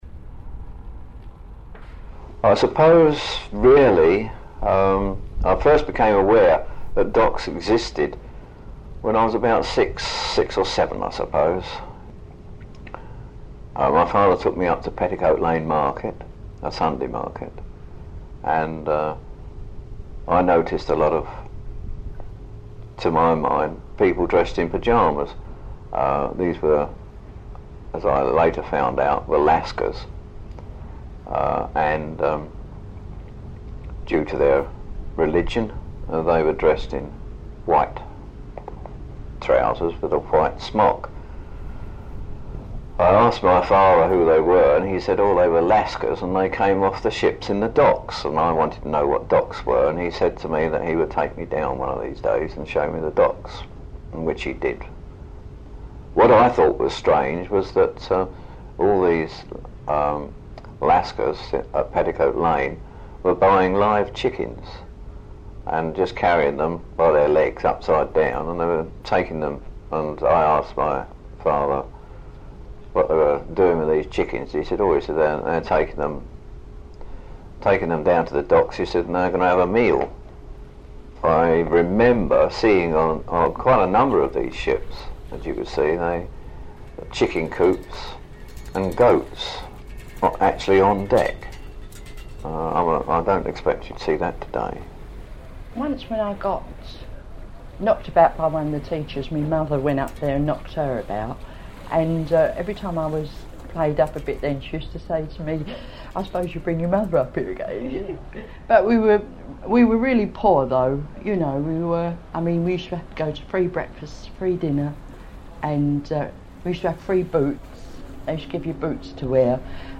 Memoryscape logo Voices from the hidden history of the Thames
Listen out for a stick running against the railings - there are child-ghosts here.